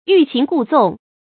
yù qín gù zòng
欲擒故纵发音